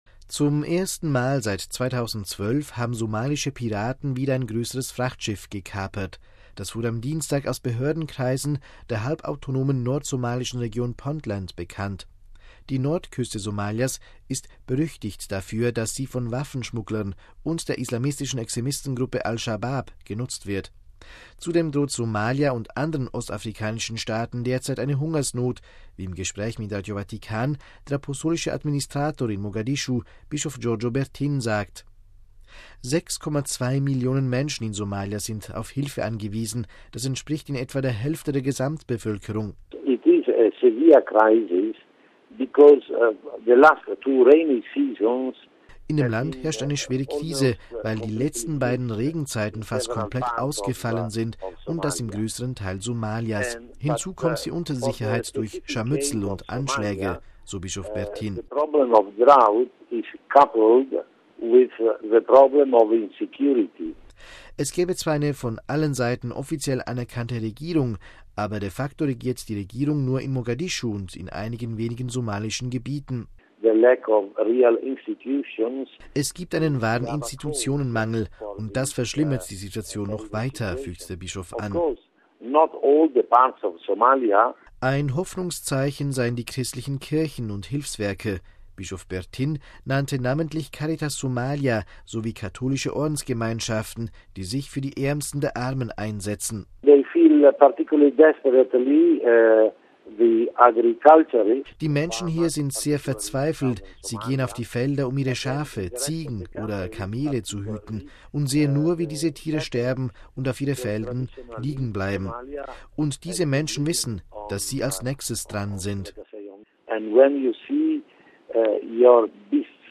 Zudem droht Somalia und anderen ostafrikanischen Staaten derzeit eine Hungersnot, wie im Gespräch mit Radio Vatikan der Apostolische Administrator in Mogadischu, Bischof Giorgio Bertin, sagt.